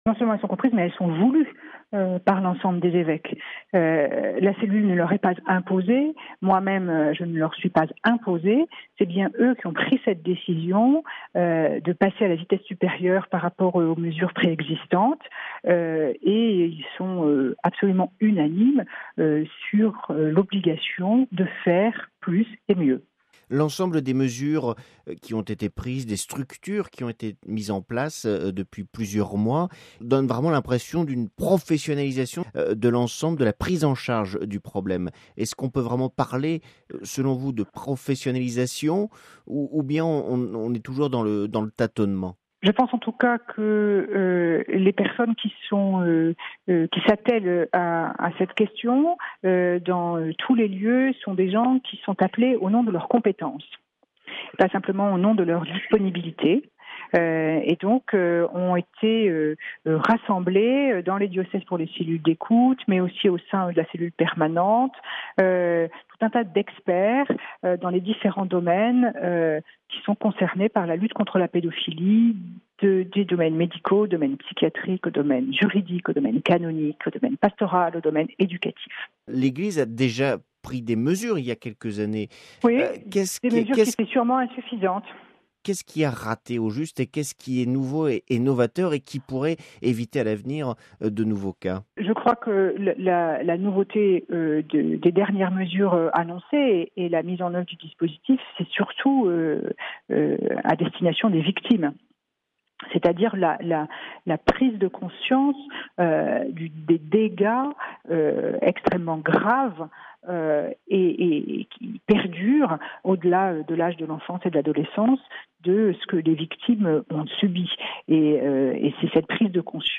(RV) Entretien – Les évêques français plus que jamais impliqués dans la lutte contre la pédophilie.